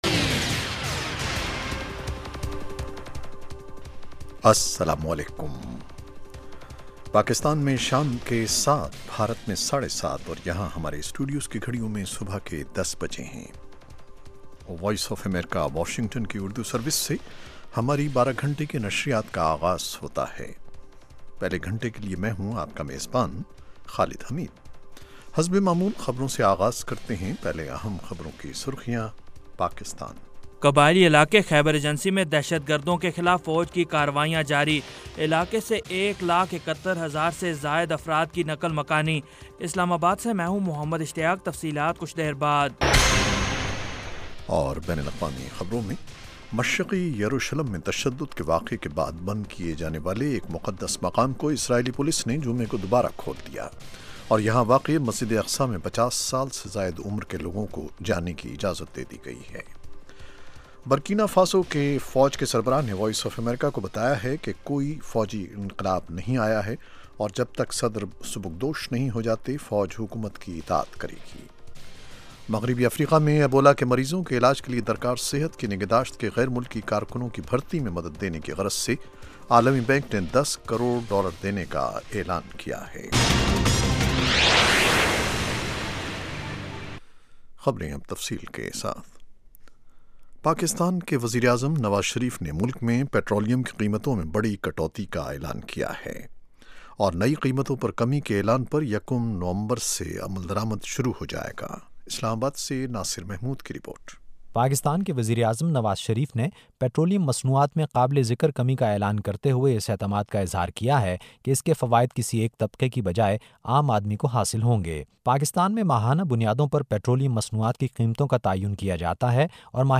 7:00PM اردو نیوز شو اس ایک گھنٹے میں دن بھر کی اہم خبریں اور پاکستان اور بھارت سے ہمارے نمائندوں کی روپورٹیں پیش کی جاتی ہیں۔ اس کے علاوہ انٹرویو، صحت، ادب و فن، کھیل، سائنس اور ٹیکنالوجی اور دوسرے موضوعات کا احاطہ کیا جاتا ہے۔